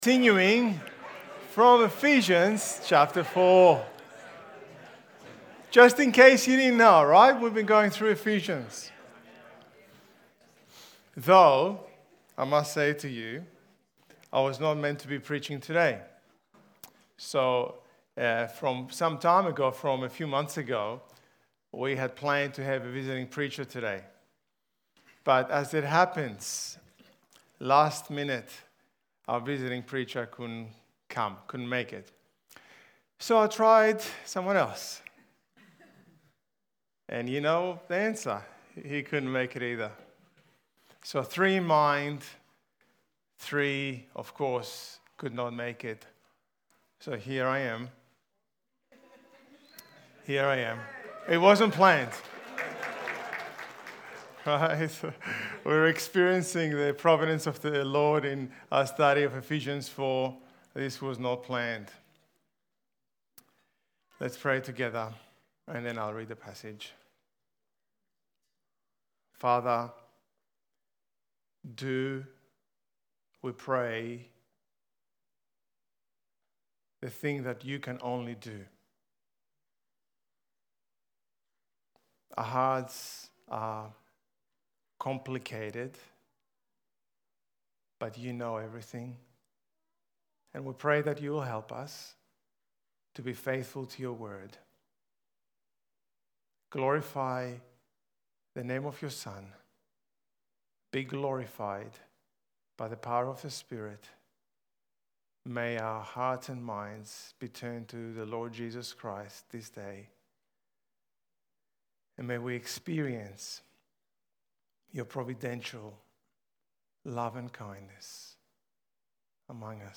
Ephesians Sermon Series